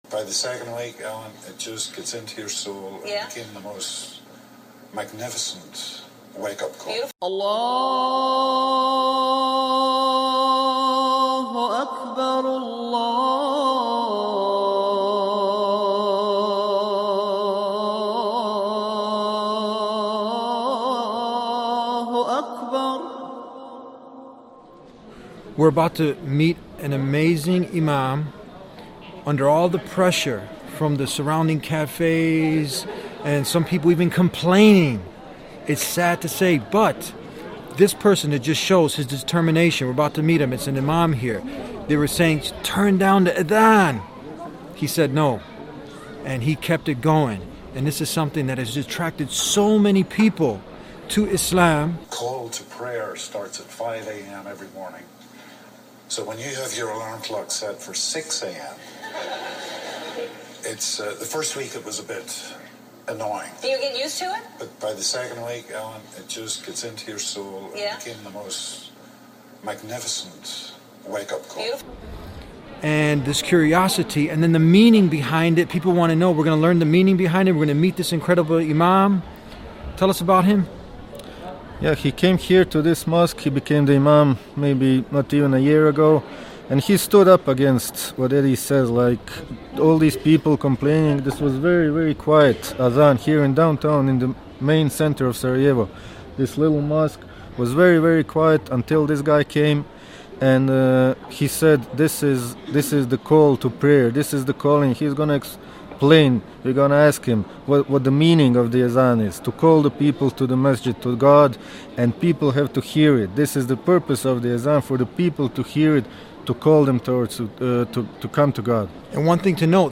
Turn up the Azan
We are in Sarajevo, capital of Bosnia and Herzegovina congratulating an Imam who turned up the AZAN God willing more will follow his lead and turn up the AZAN find out more about the Azan it’s meaning and why Liam Neeson loves the Azan describing it as getting into his soul